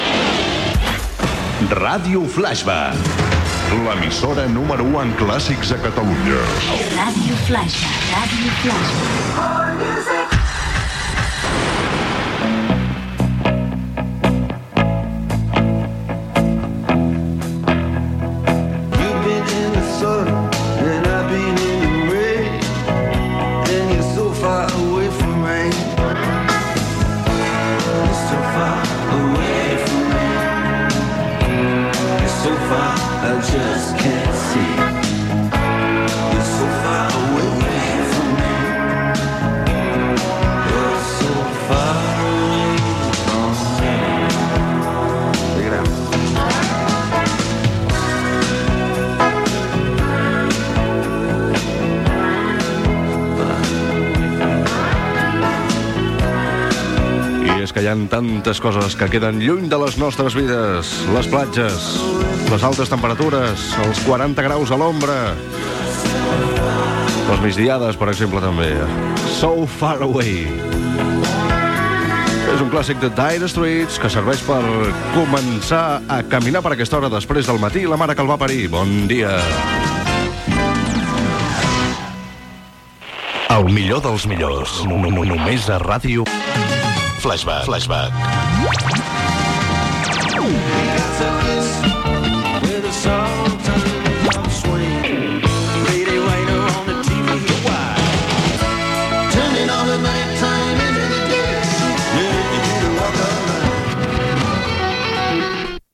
Indicatiu de la ràdio, tema musical, comentari inicial de l'hora, indicatiu de la ràdio
Musical
FM